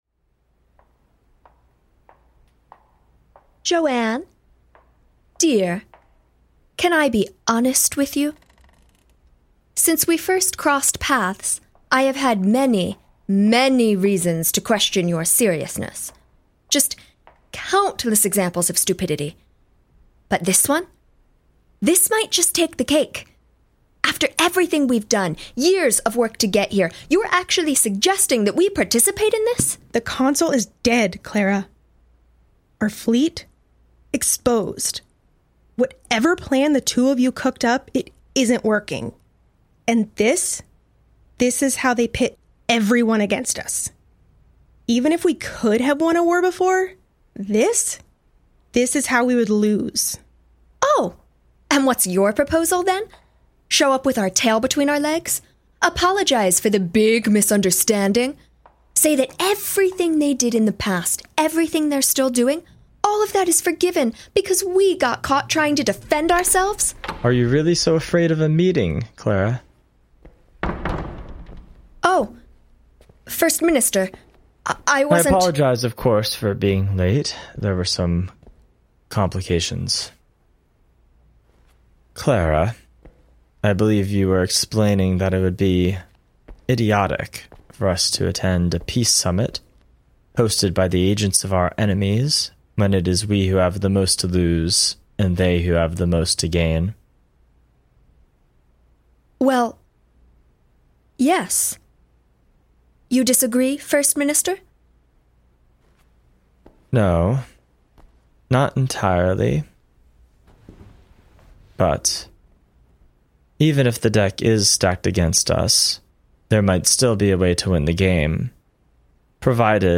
Worlds Away is an actual-play storytelling podcast where five close friends use games to create adventures together as a collective.